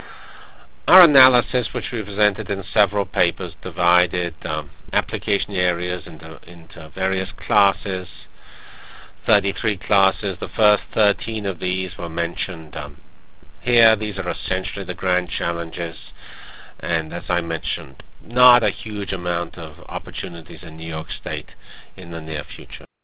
From HPCS95 Keynote Presentation: HPC at the Crossroads Academic Niche or Economic Development Cornucopia HPCS95 Symposium -- July 10-12 Montreal Canada.